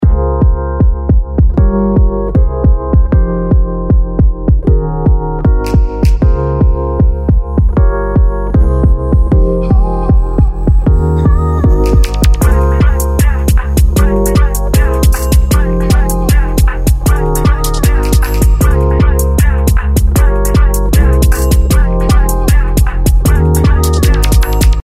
Classic 1200 Basslines」も素晴らしく、90年代サンプラーの名機を通したようなあのぶっといベースのようになります。